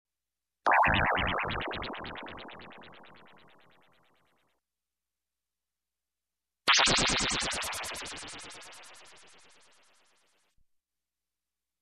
Электронное оружие звуки скачать, слушать онлайн ✔в хорошем качестве